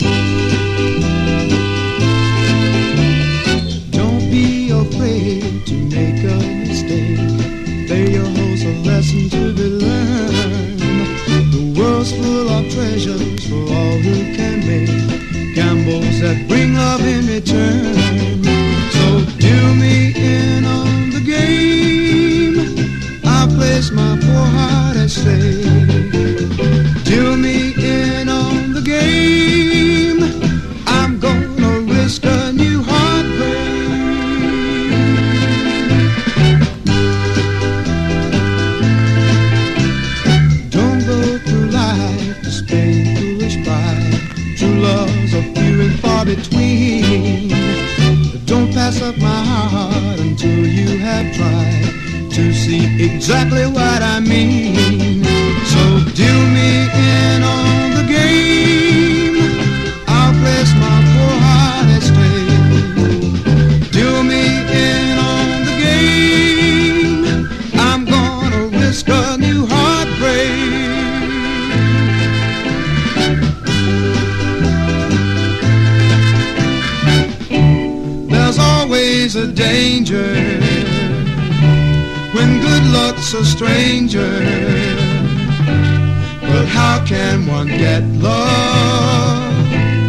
SOUL / SOUL / 60'S / NORTHERN SOUL (UK)